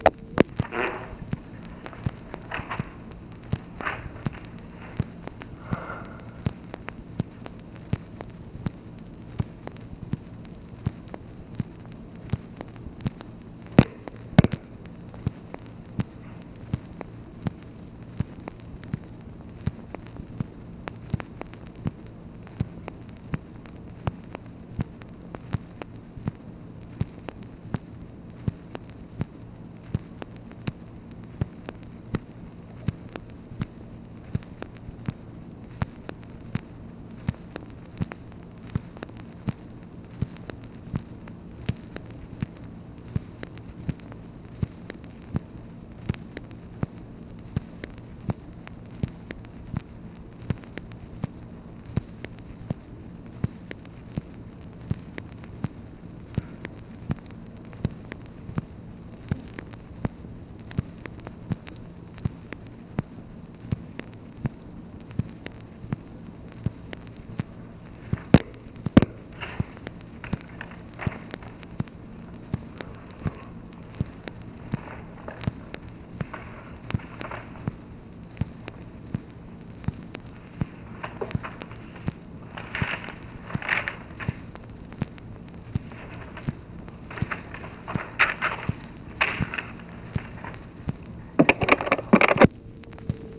OFFICE NOISE, April 10, 1964
Secret White House Tapes | Lyndon B. Johnson Presidency